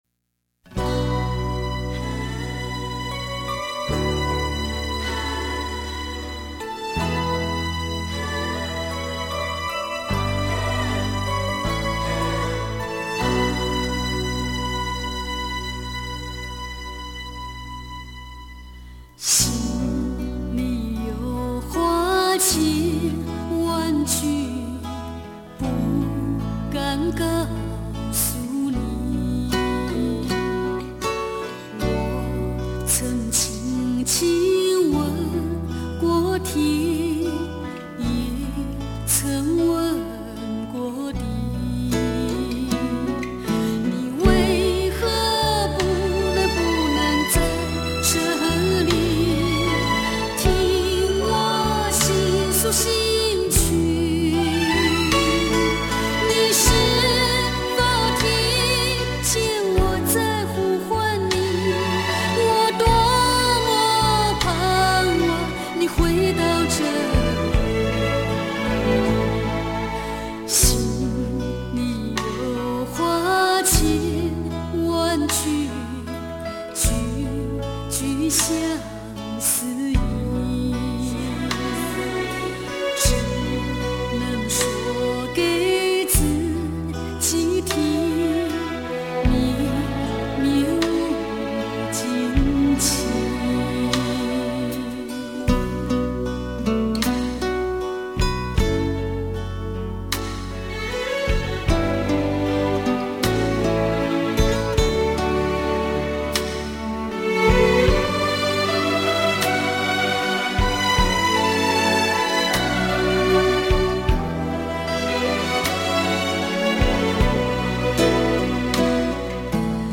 国语原声